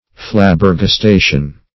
flabbergastation.mp3